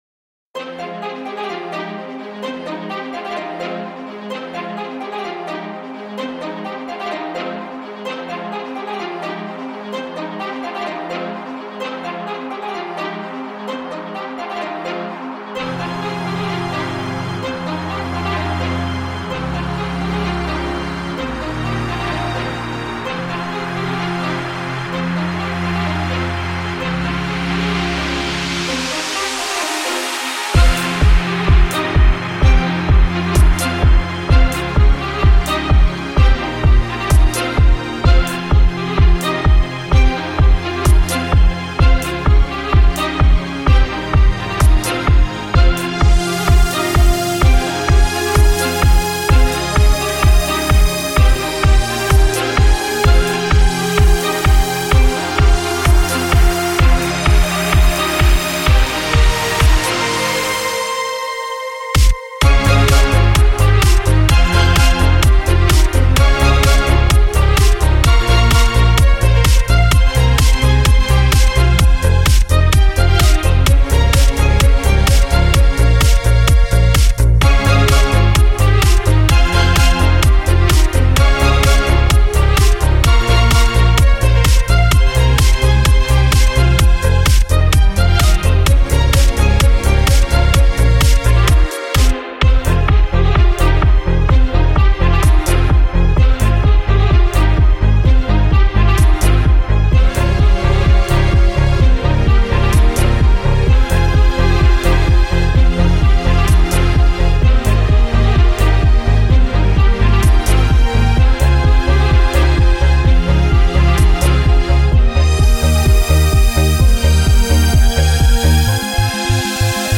genre:orchestral house